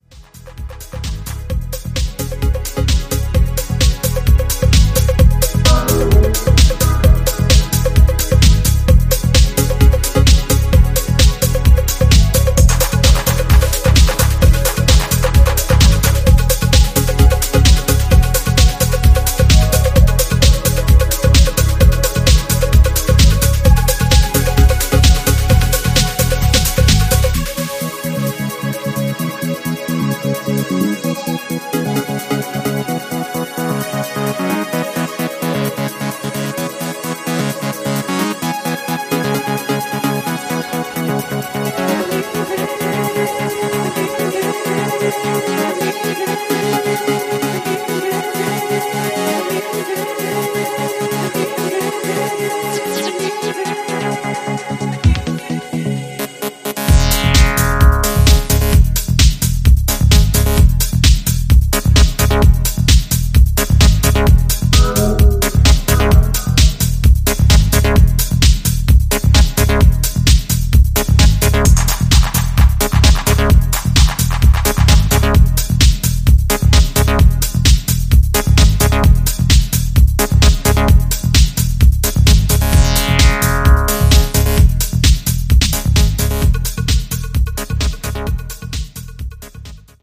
いずれの楽曲もソリッドでカラフル、そしてポジティヴなパワーで溢れたピークタイム・チューン！